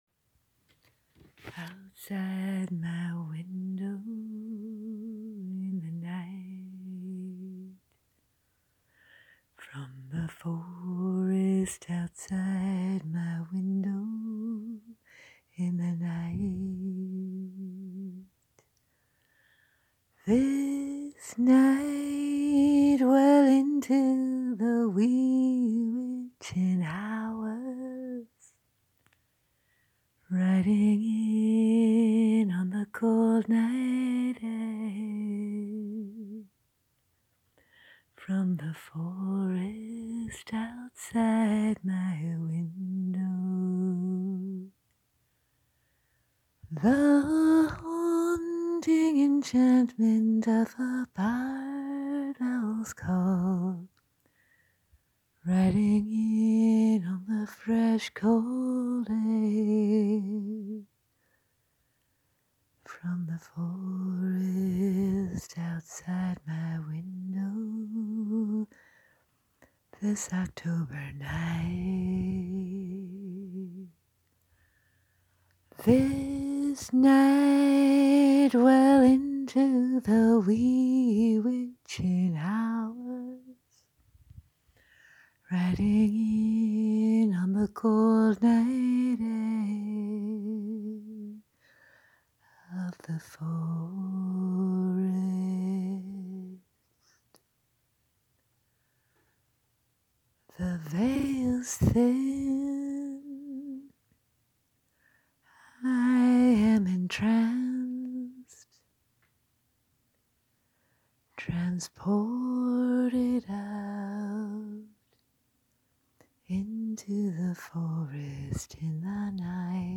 musical interpretation